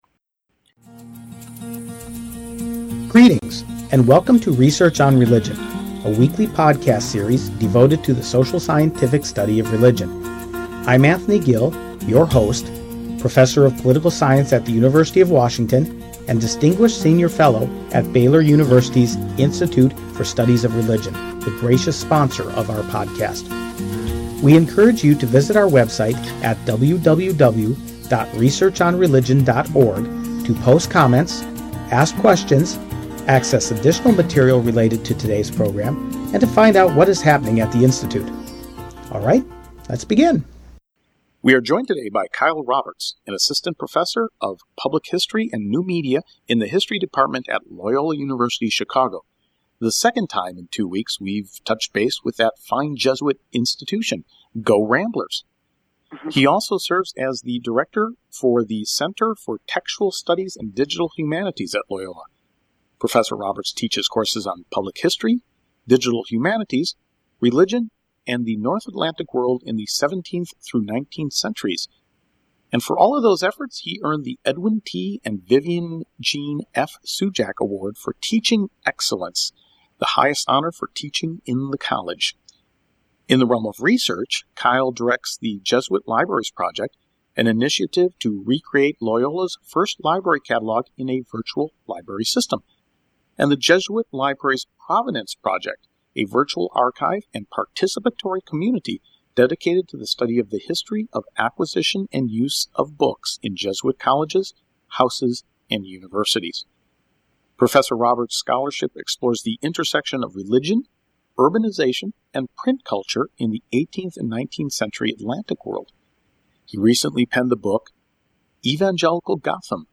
The interview is peppered with vivi